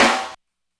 snare.wav